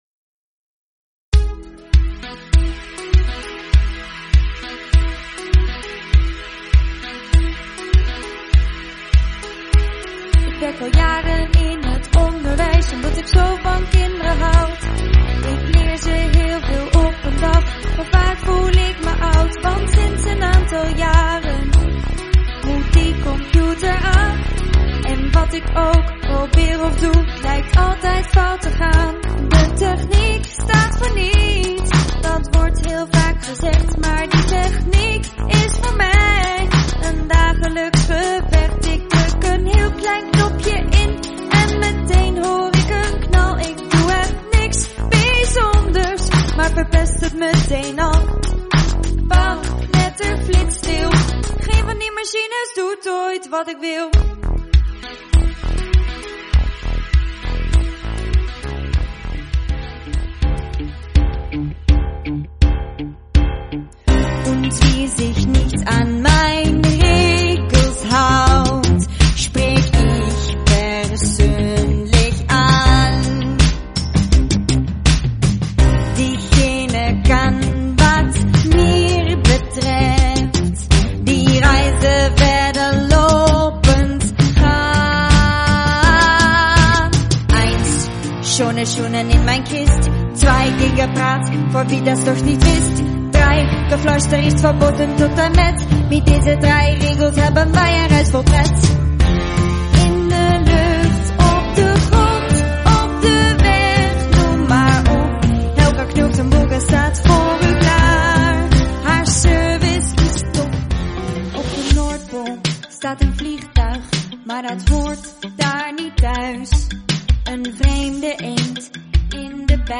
Dit is een muziekfragment van de liedjes.